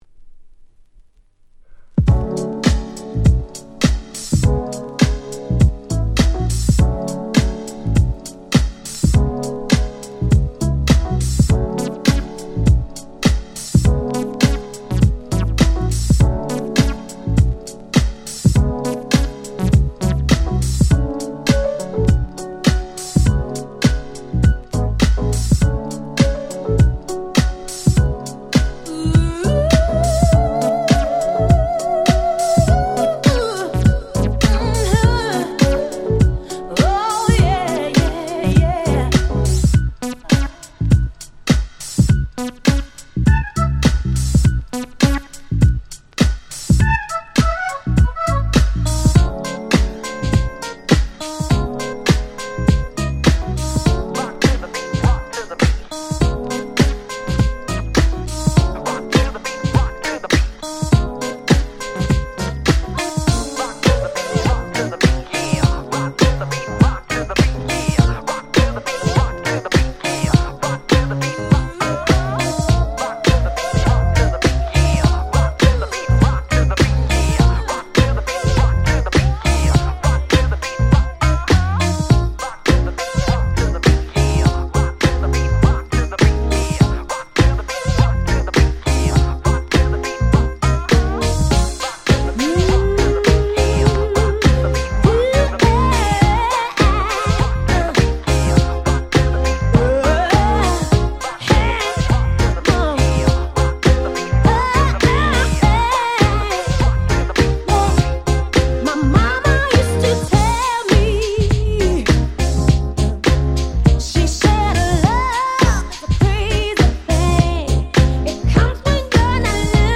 Nice Mash Up / Remix !!